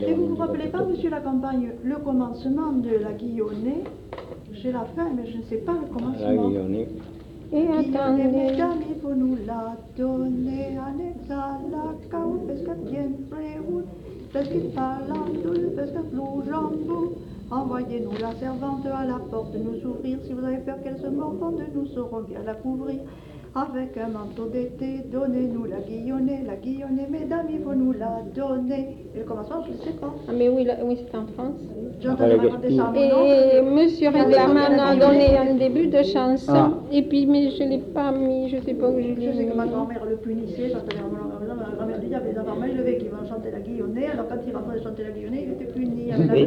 Lieu : Bazas
Genre : chant
Effectif : 1
Type de voix : voix de femme
Production du son : chanté